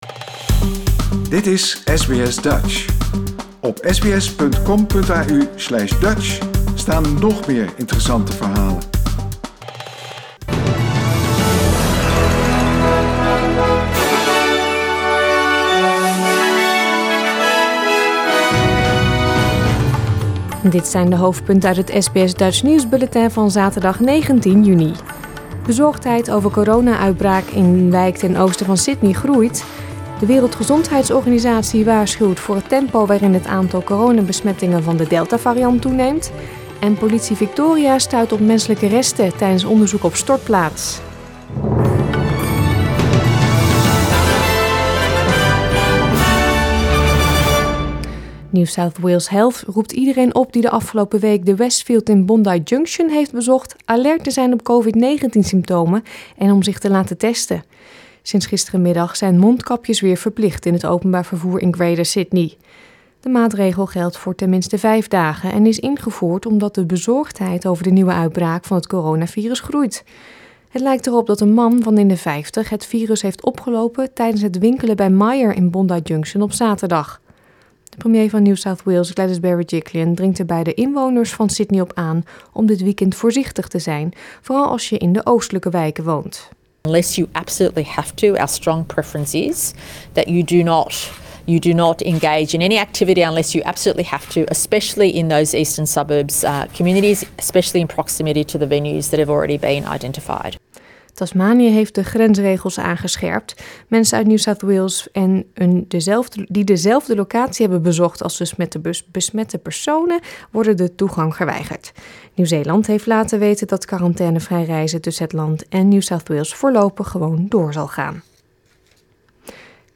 Nederlands/Australisch SBS Dutch nieuwsbulletin van zaterdag 19 juni 2021